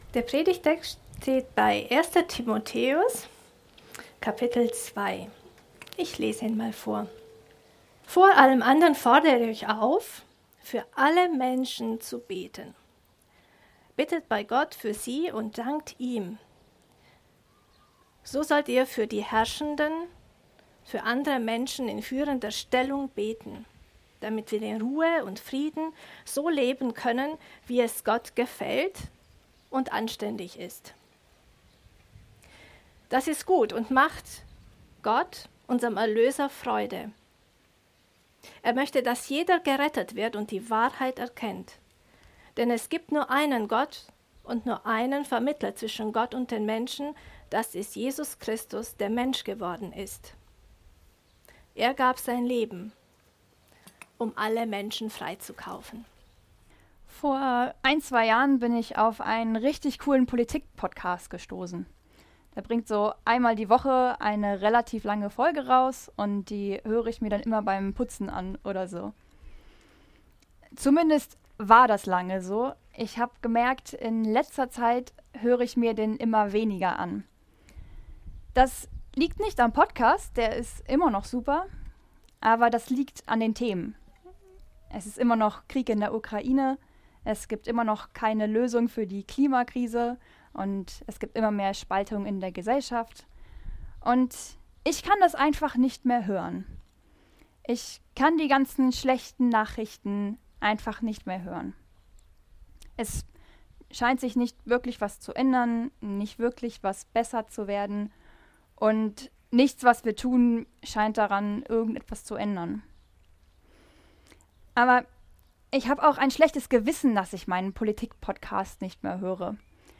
GreifBar+ Gottesdienst